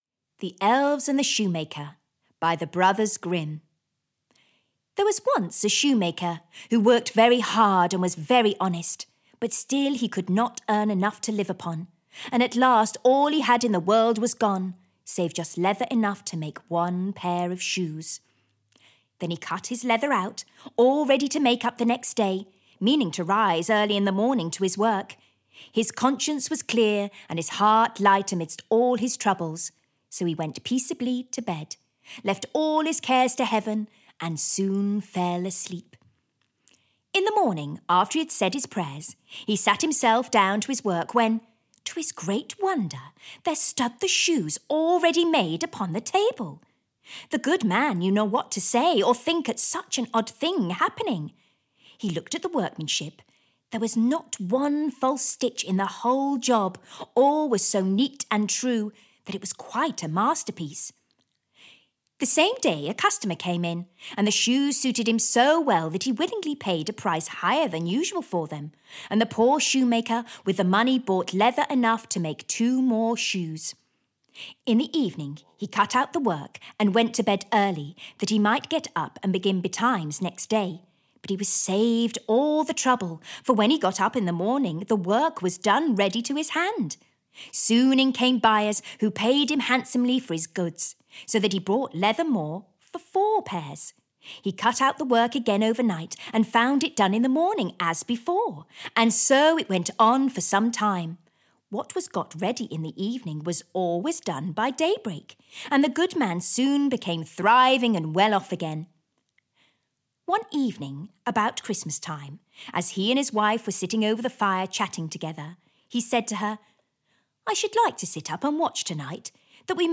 Stories for Young Children (EN) audiokniha
Ukázka z knihy